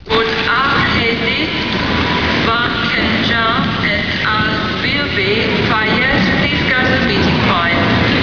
Two guys have wasted their time at airports, in this case, London’s Heathrow, by writing down strange names and asking the airport Information Center to locate these people by calling out their names on the airport’s PA system.  These guys would then hang out beneath the speakers and record the results.